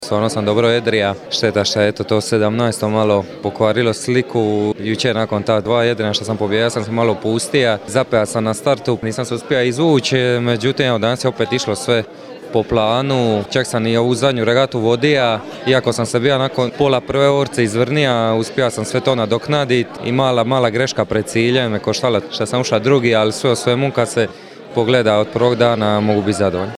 MP3 izjava